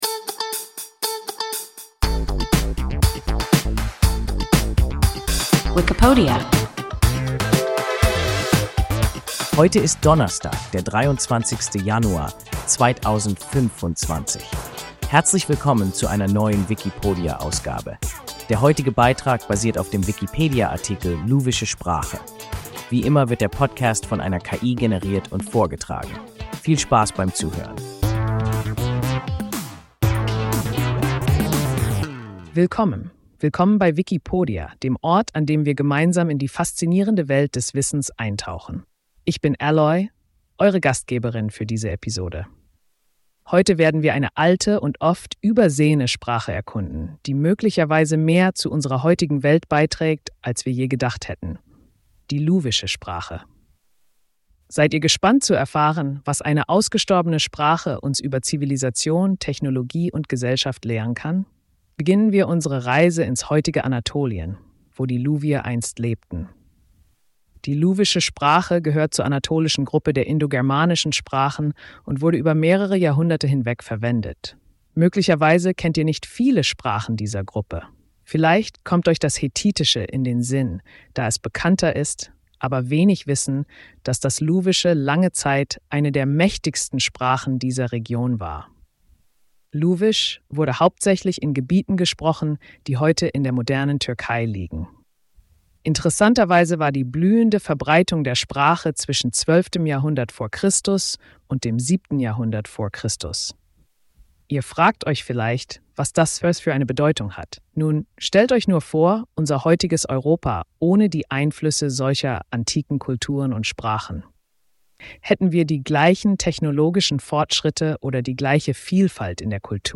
Luwische Sprache – WIKIPODIA – ein KI Podcast